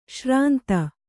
♪ śrānta